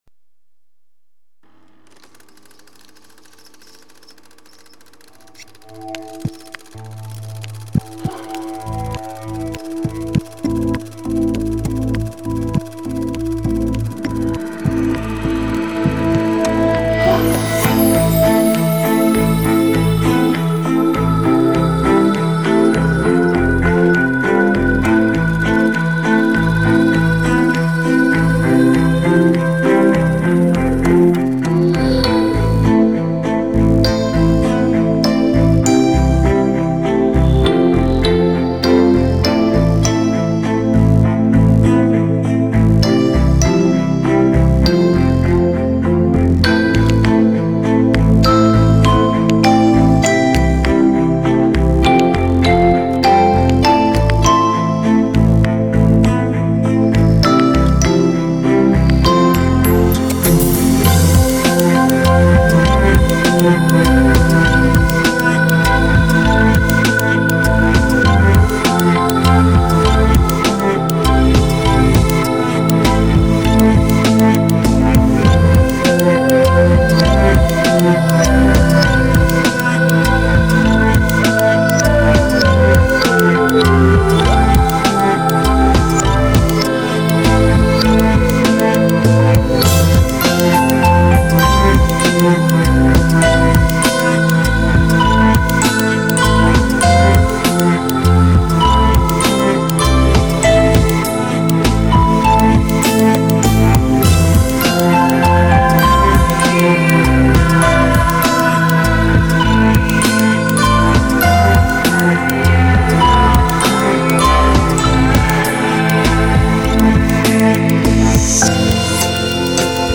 New age Медитативная музыка Нью эйдж